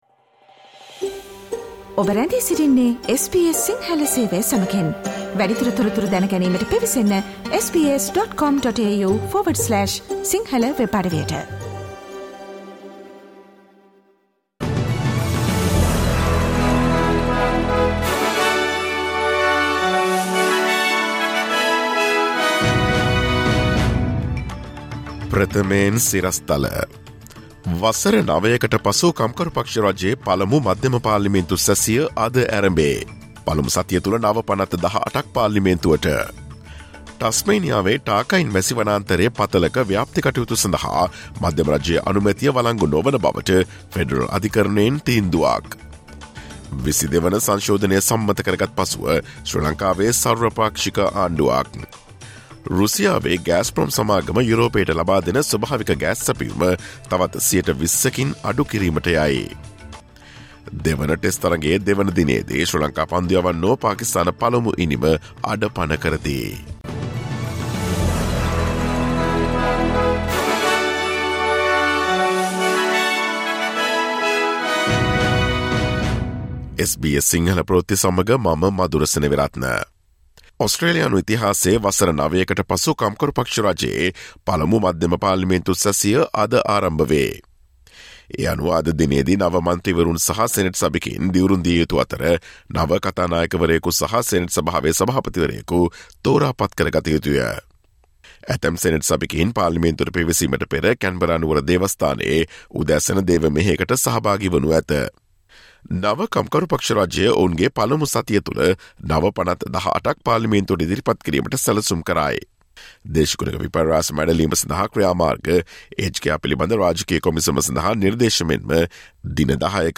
ඔස්ට්‍රේලියාවේ සහ ශ්‍රී ලංකාවේ නවතම පුවත් මෙන්ම විදෙස් පුවත් සහ ක්‍රීඩා පුවත් රැගත් SBS සිංහල සේවයේ 2022 ජූලි 26 වන දා අඟහරුවාදා වැඩසටහනේ ප්‍රවෘත්ති ප්‍රකාශයට සවන් දීමට ඉහත ඡායාරූපය මත ඇති speaker සලකුණ මත click කරන්න.